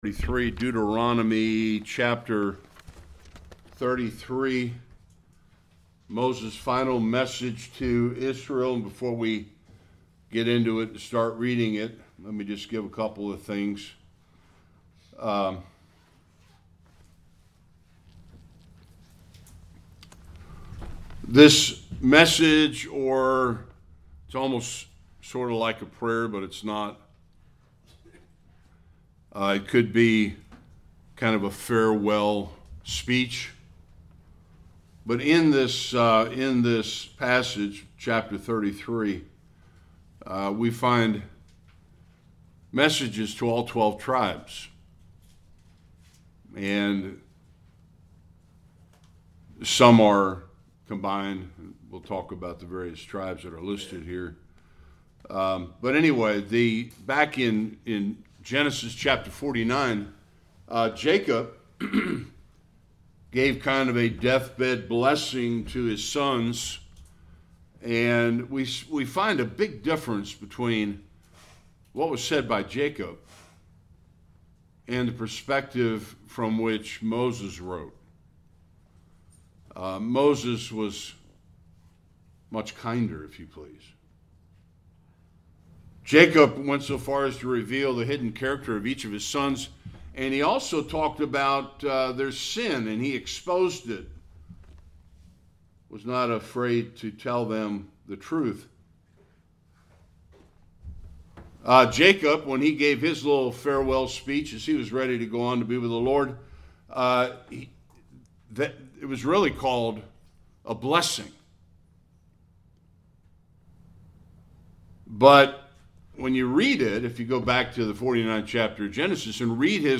1-7 Service Type: Sunday School Moses began his farewell speech by extolling the greatness of God.